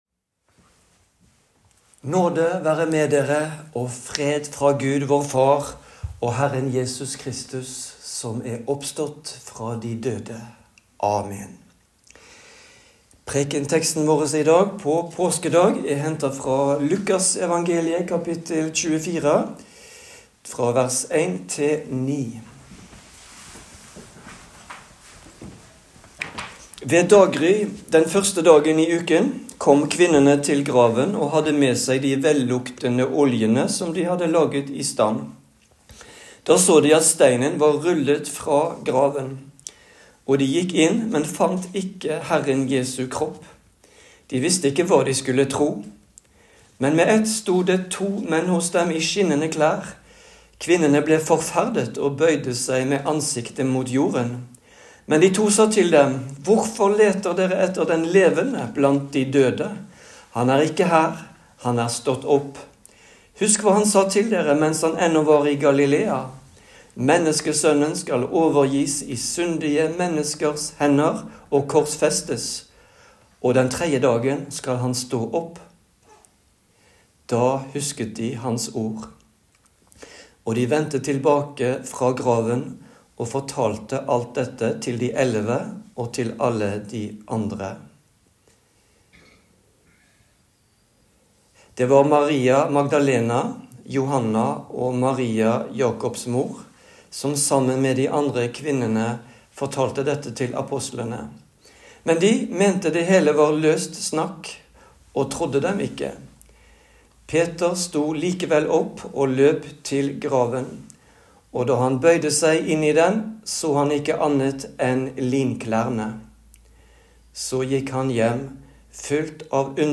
Påskedagspreken over Luk 24,1-12